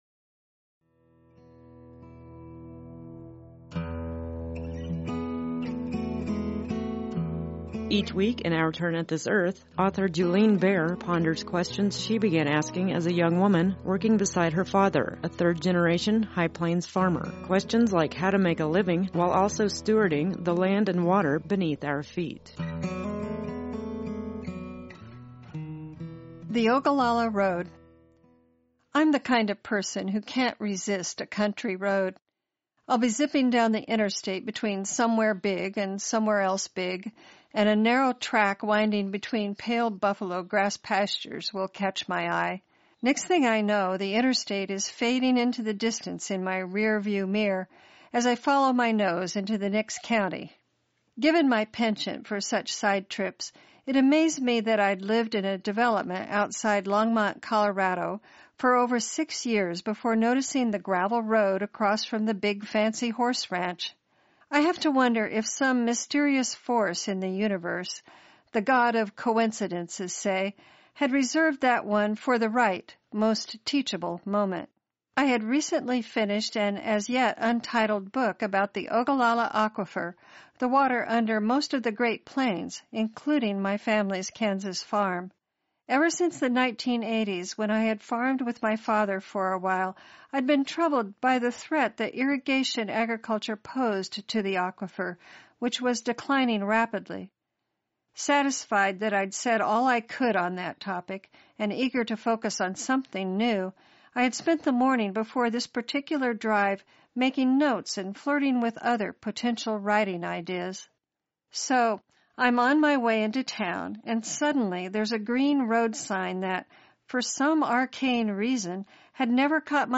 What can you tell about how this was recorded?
“Our Turn at this Earth” airs weekly on High Plains Public Radio.